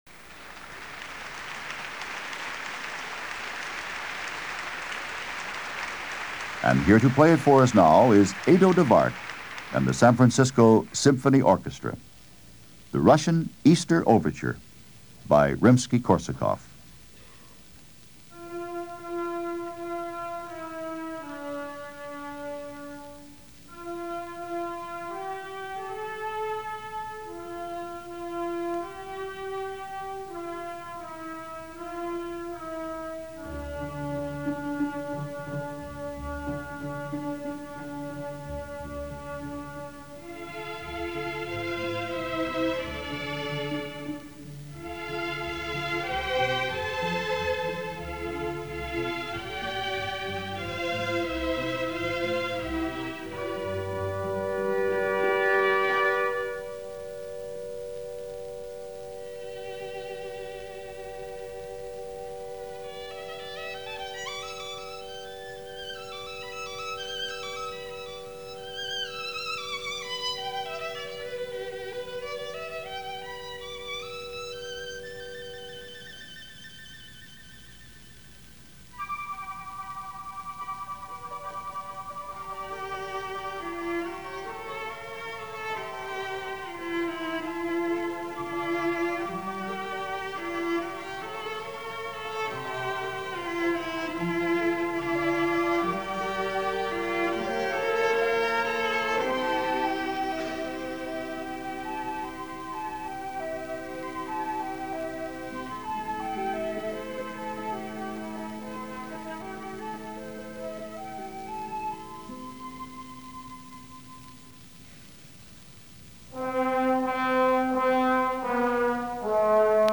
Back to rarities this week. A concert by the San Francisco Symphony led by its then-Music Director Edo DeWaart and featuring the legendary Russian Cellist Mstislav Rostropovich in music of Mozart, Dvorak, Rimsky-Korsakov and Tchaikovsky.
The legendary Rostropovich this week, playing Dvorak.